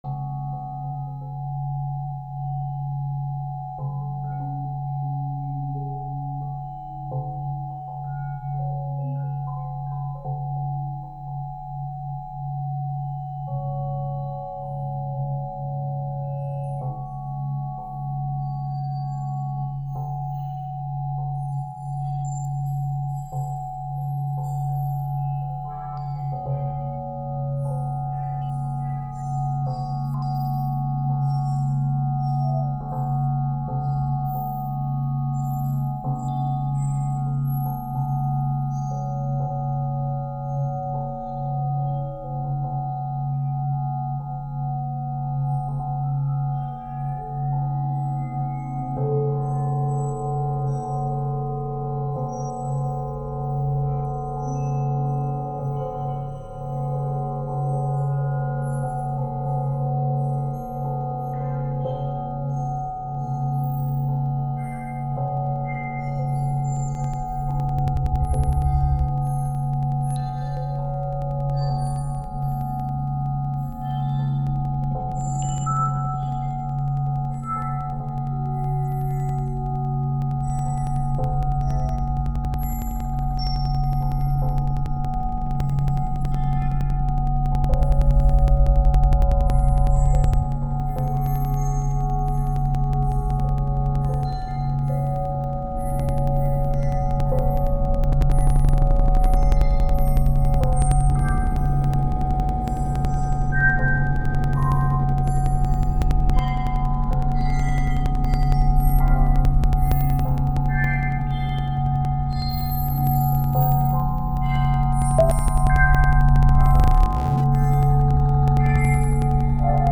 introspective and spatial ambient, of the dark kind
breaking glitches and examining computer entrails